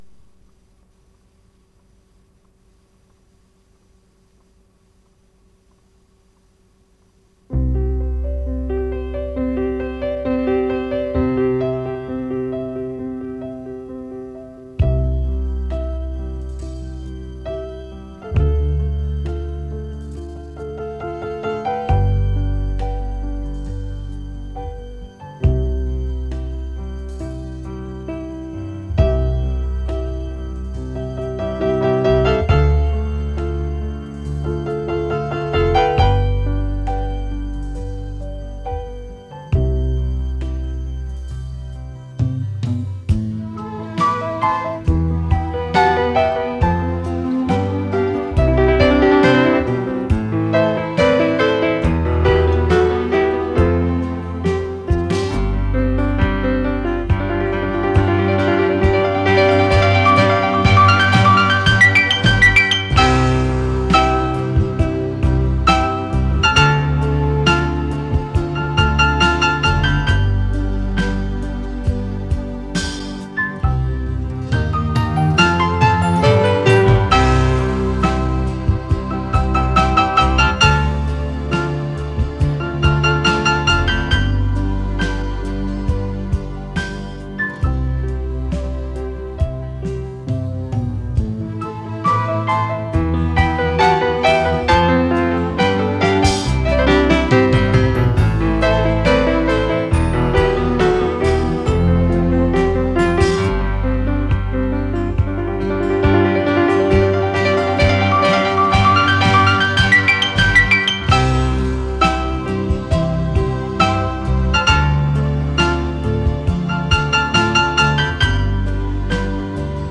磁带数字化：2022-10-09
现代浪漫钢琴曲